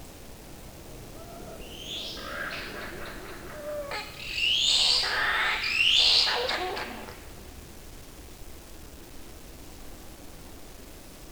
A PAM unit was deployed to capture nocturnal vocalisations, and semi-automated analysis confirmed their presence through their loud, distinctive shrieking calls.
Yellow-bellied glider call recorded at this location